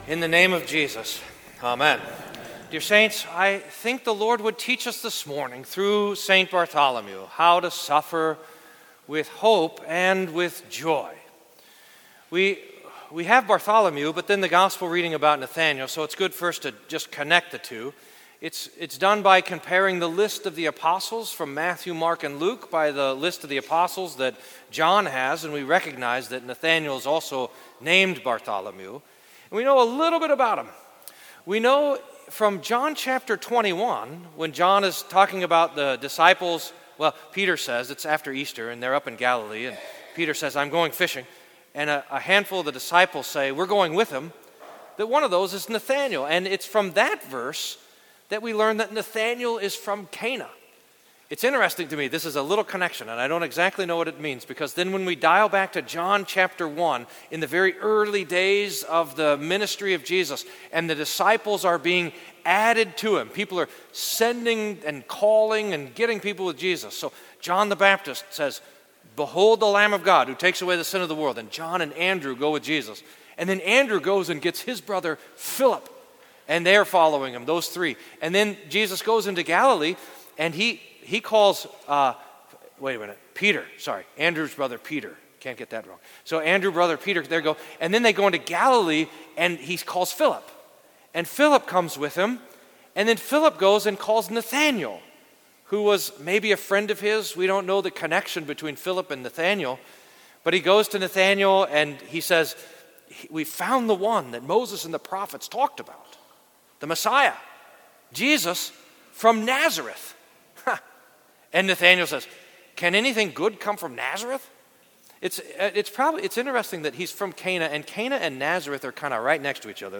Sermon for St. Bartholomew, Apostle